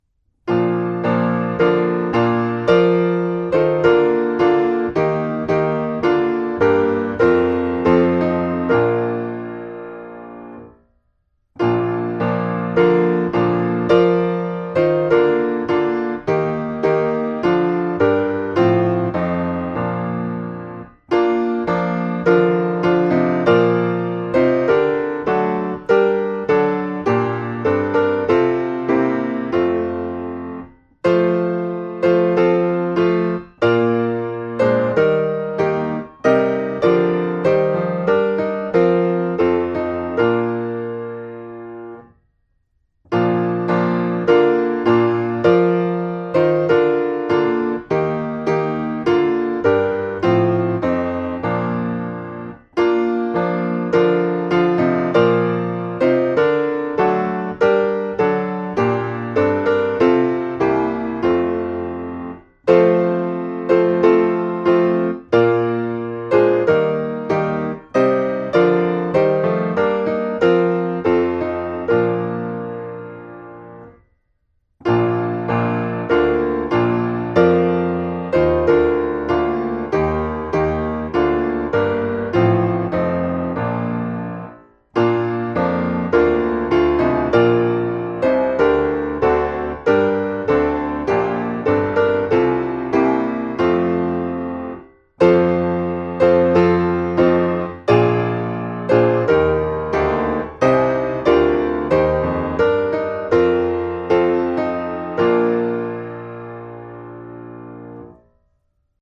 220-Angels_from_the_Realms_of_Glory-piano.mp3